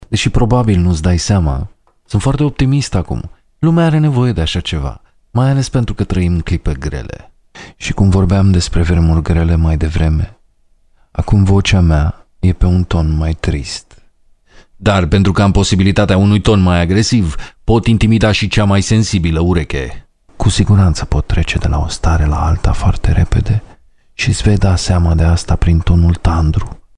罗马尼亚语中年沉稳 、娓娓道来 、素人 、男专题片 、宣传片 、纪录片 、广告 、飞碟说/MG 、课件PPT 、工程介绍 、绘本故事 、动漫动画游戏影视 、120元/百单词男罗07 罗马尼亚语男声 干音1 沉稳|娓娓道来|素人